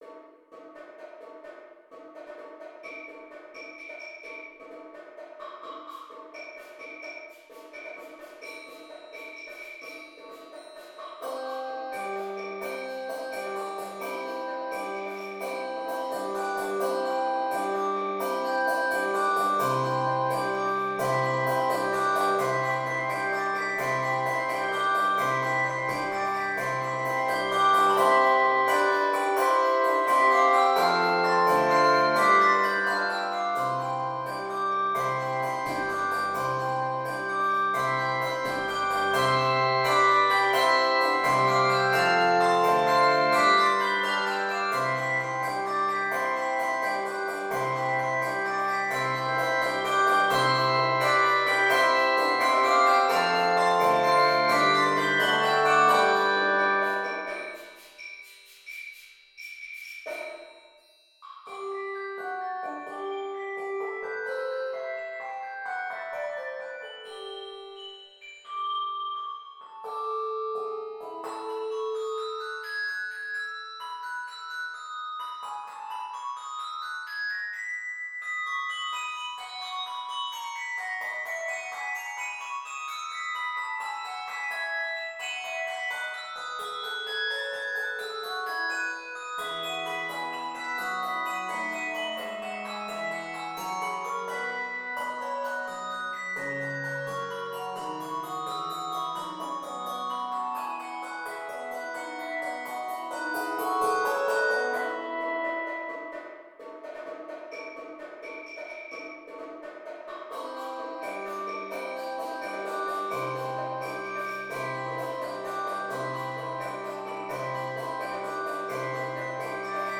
N/A Octaves: 5 Level